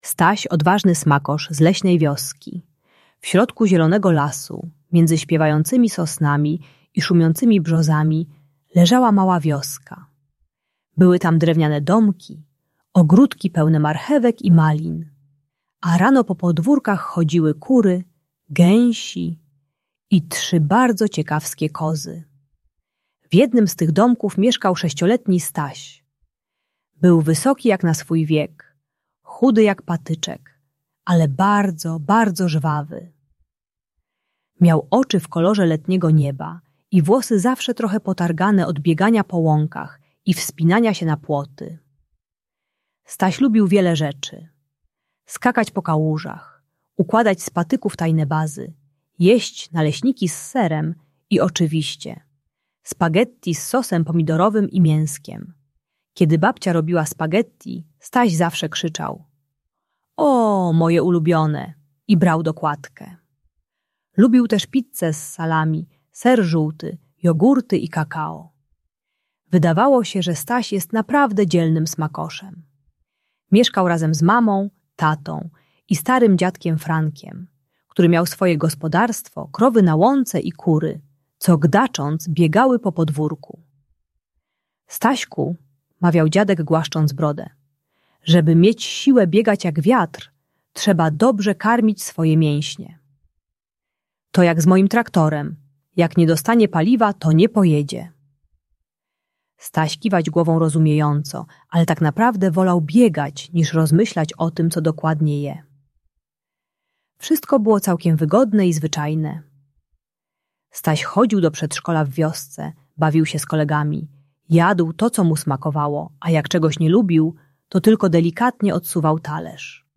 Staś, Odważny Smakosz - Problemy z jedzeniem | Audiobajka